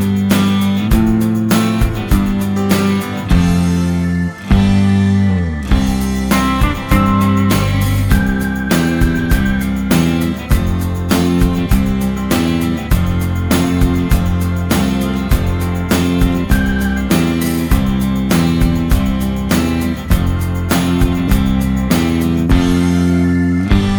Pop (1990s)